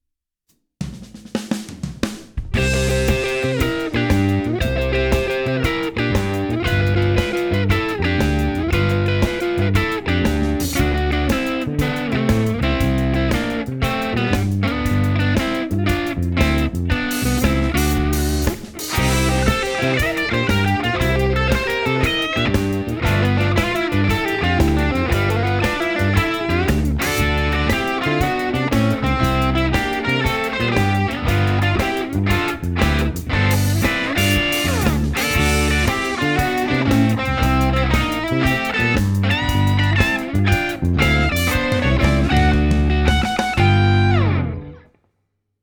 8-Bar Blues